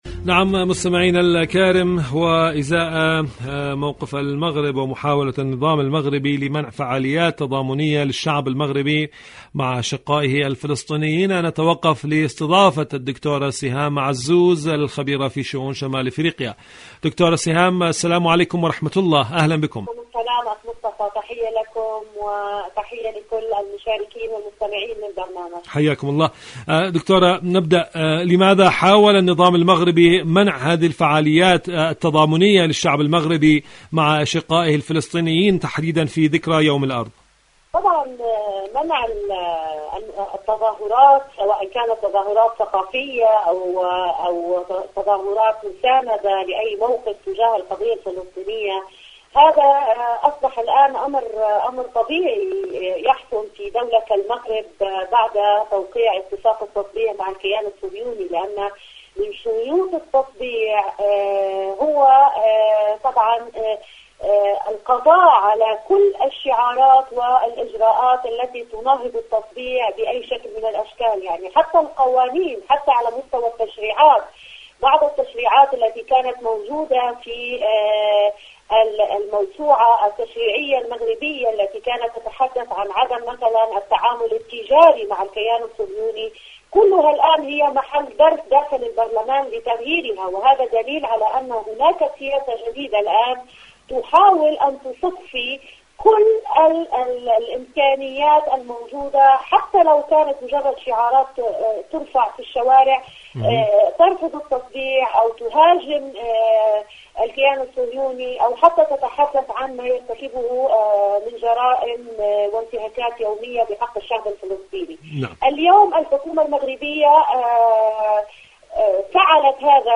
مقابلات مقابلات إذاعية الحكومة المغربية برامج إذاعة طهران برنامج صدى المغرب العربي التكشير عن الأنياب المغرب شاركوا هذا الخبر مع أصدقائكم ذات صلة إيران تعري الغطرسة الصهيوأميركية في المنطقة..